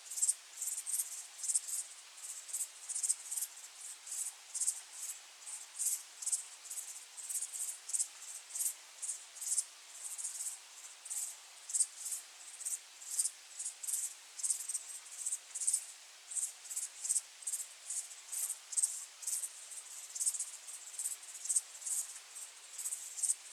crickets_2.ogg